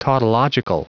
Prononciation du mot tautological en anglais (fichier audio)
Prononciation du mot : tautological